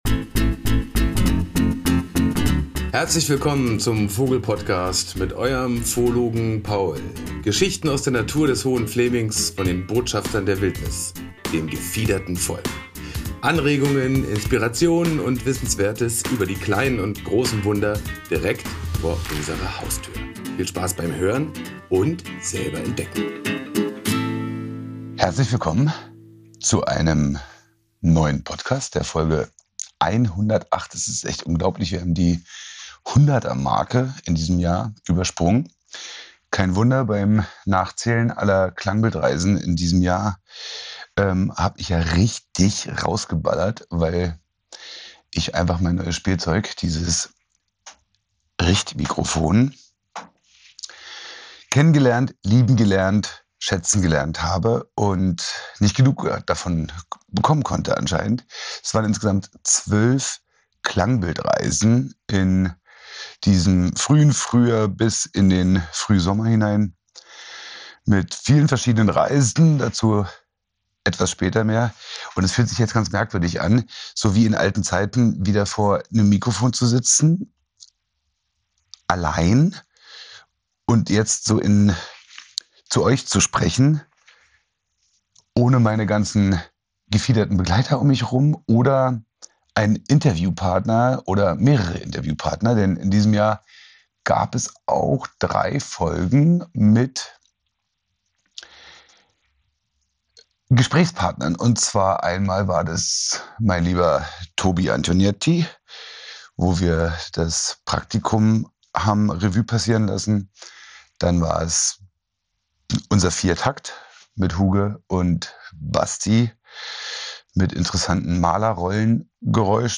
Während draußen der erste Schnee fällt, sitze ich im Studio, blättere durch mein Naturtagebuch und schaue auf mein Vogeljahr zurück.